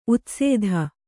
♪ utsēdha